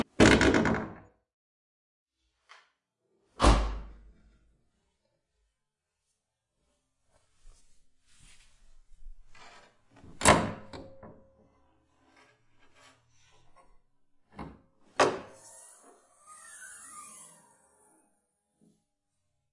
机电工程 " 车窗降
描述：欧宝Astra AH电动窗的开启，从车内录制。最后的 "咔嚓 "声是车窗开关被释放的声音，严格来说不是车窗的声音。
Tag: 滑动 双耳 汽车 电动马达 电动窗 场 - 记录 开放式 电动车窗 汽车 窗口